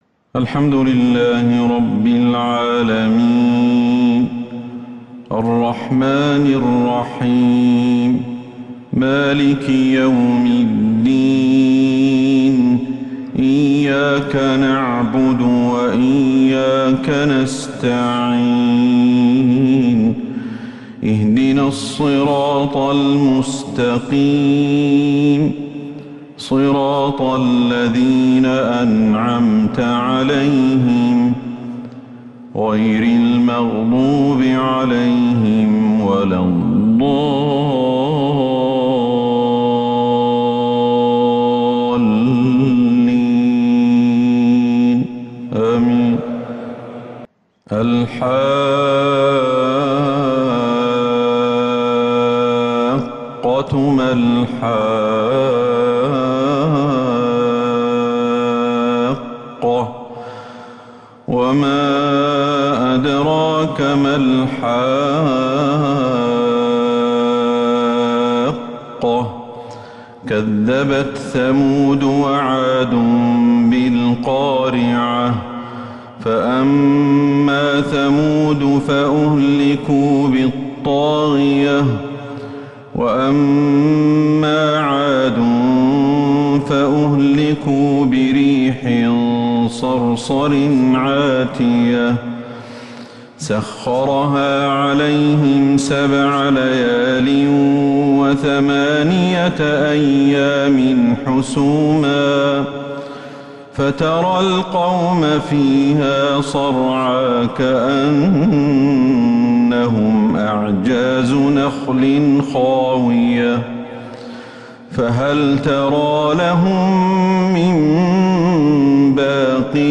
Fajr prayer from surah Al-Haqa 5-6-2022 > 1443 هـ > الفروض - تلاوات